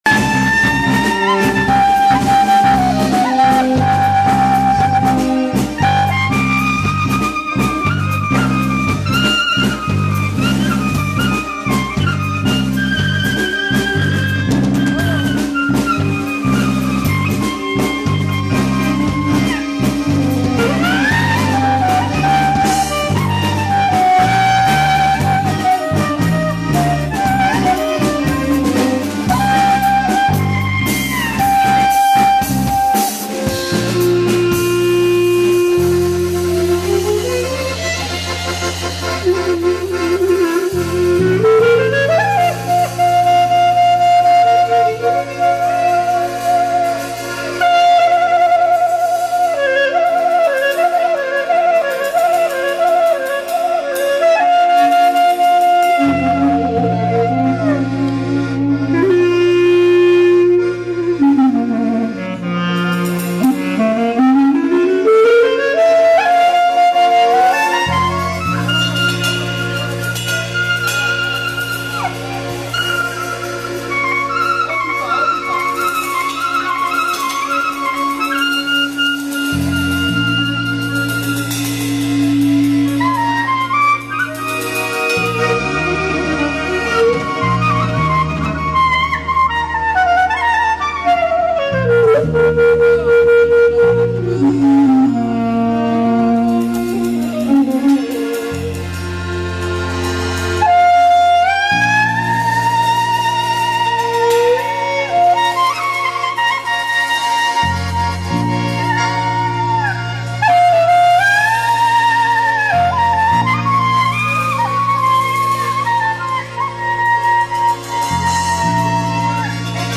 משתפך עם הקלרינט